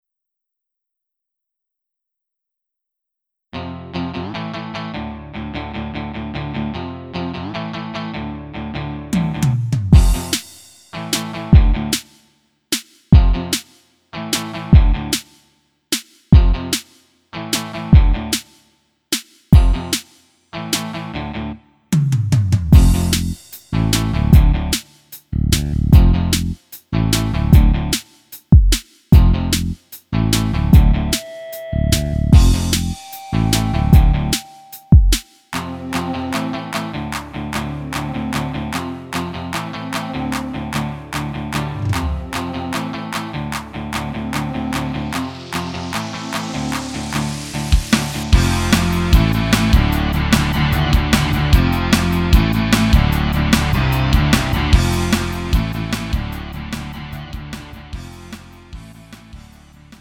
음정 원키 2:50
장르 가요 구분